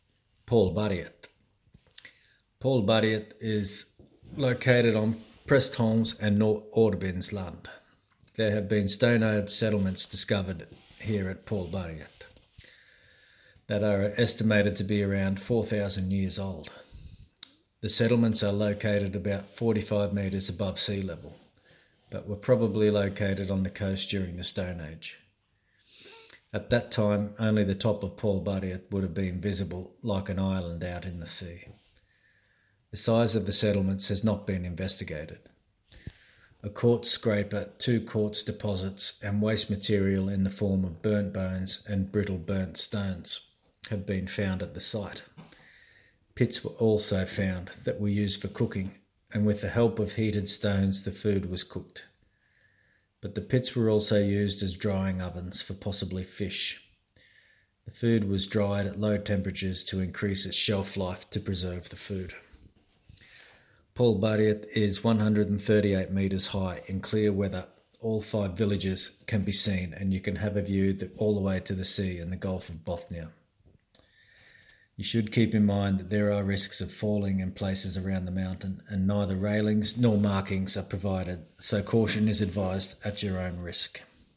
Palberget-English-voice.mp3